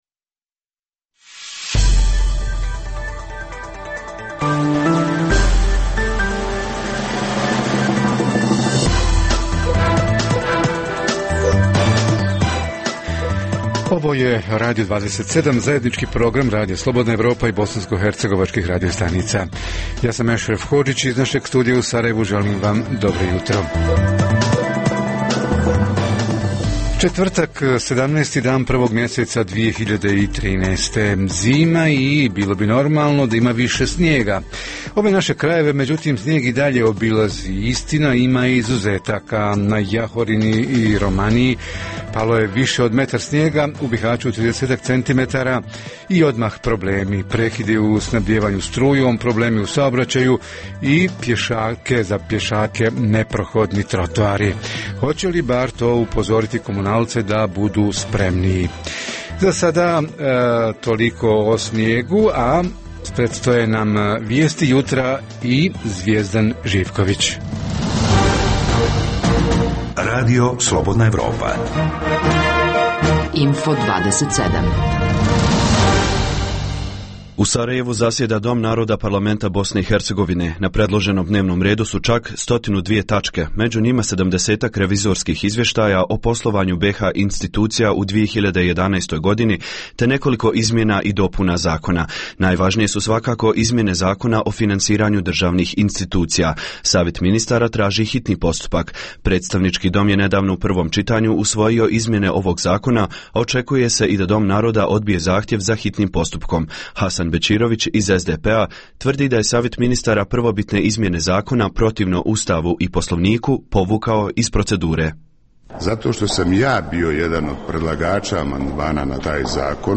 Radio 27 - Jutarnji program za BiH
- Središnja tema jutra: Kako povećati kvalitet komunalnih usluga – monopolom javnih preduzeća ili konkurencijom s privatnim firmama? O tome će naši reporteri iz Zeničko-dobojskog kantona, Konjica i Mostara.